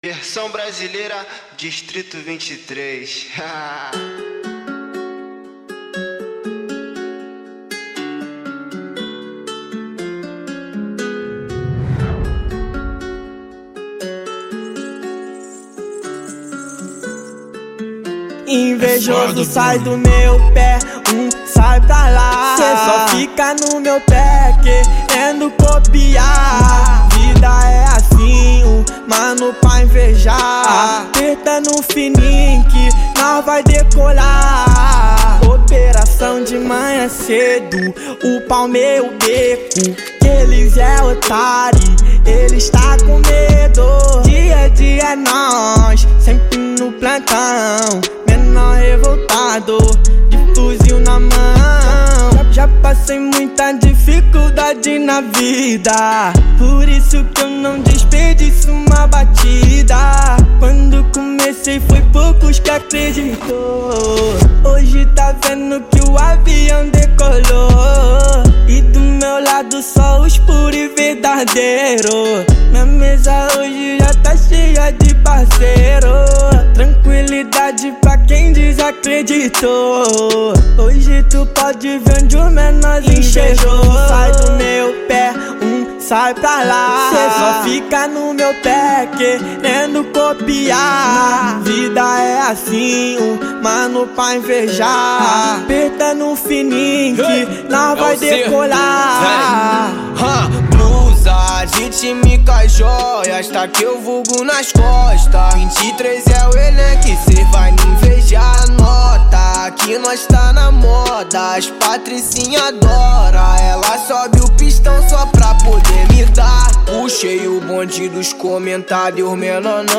2024-11-22 08:53:41 Gênero: Trap Views